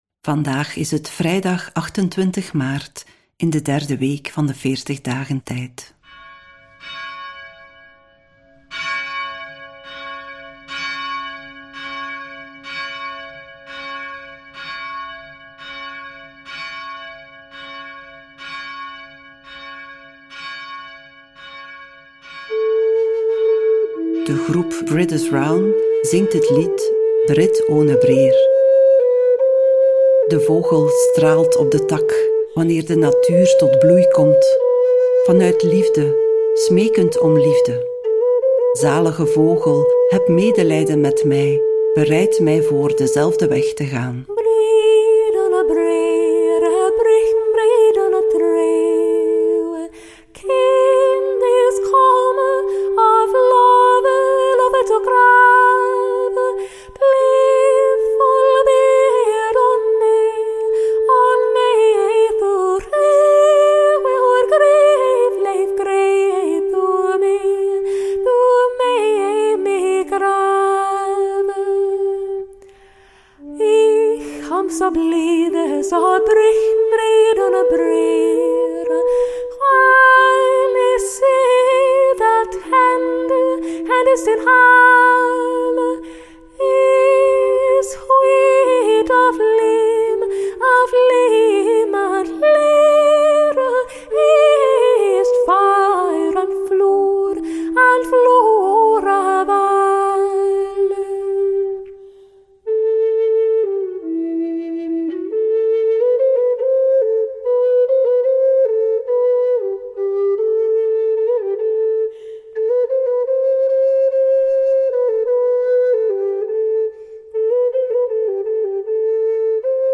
Bidden Onderweg brengt je dichter bij God, met elke dag een nieuwe gebedspodcast. In de meditaties van Bidden Onderweg staan Bijbelteksten central. De muzikale omlijsting, overwegingen y begeleidende vragen helpen je om tot gebed te komen.